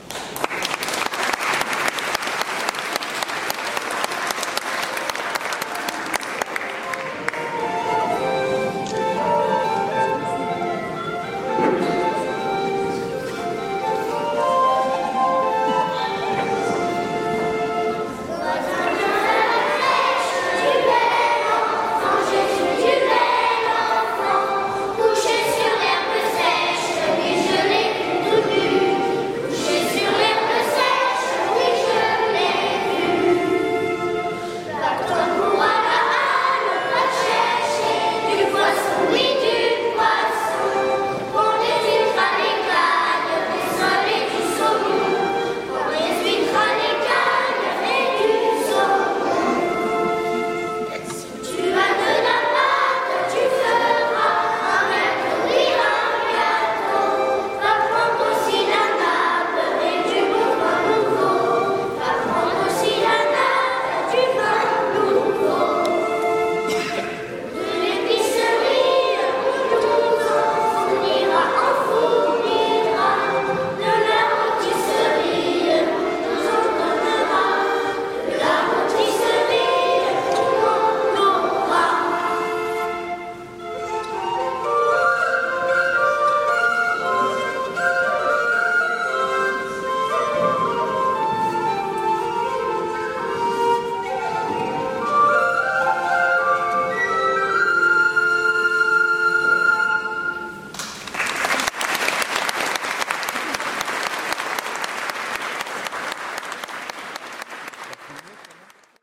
chantée par les Petits Chavans et les enfants de l’école d’Arleuf
Las Filles et Gars d’Arleu